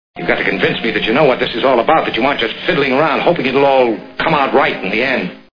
The Maltese Falcon Movie Sound Bites